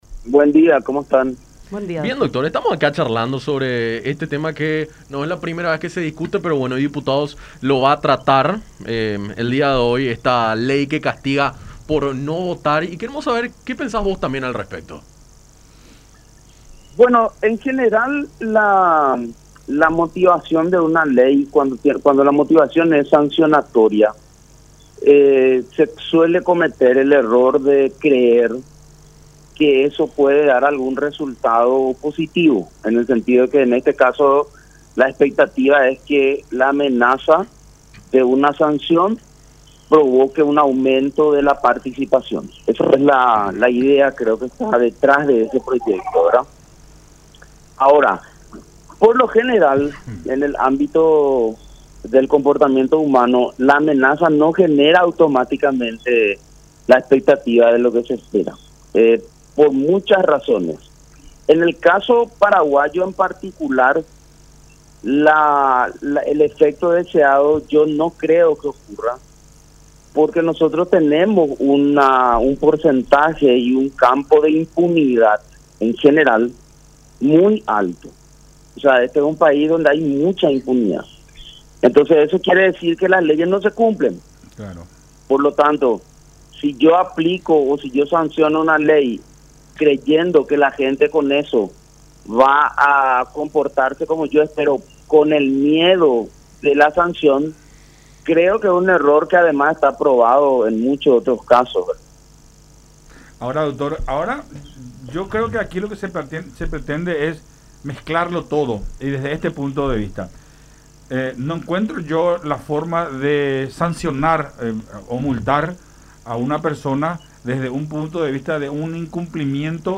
en conversación con Enfoque 800 por La Unión